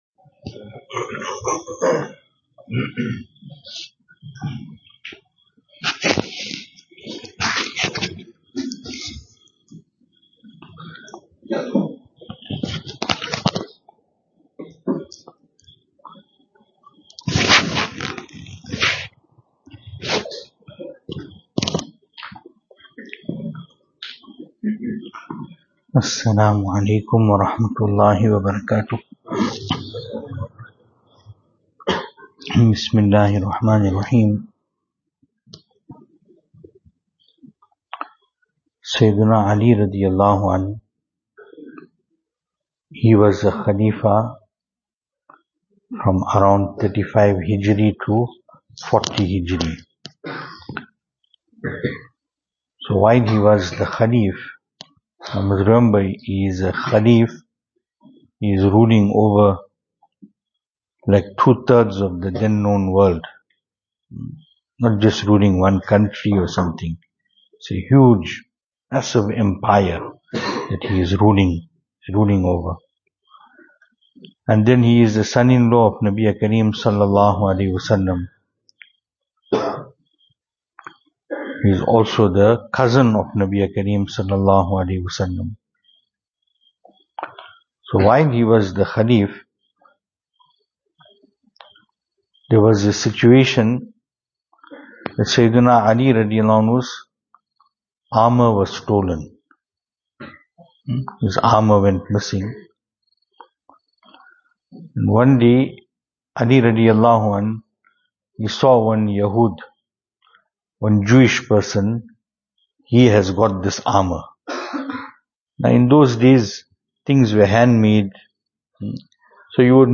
Venue: Albert Falls , Madressa Isha'atul Haq Series: Islahi Majlis Service Type: Islahi Majlis Topics: Islahi Majlis « Let us not be deceived by considering the extras in life to be necessities.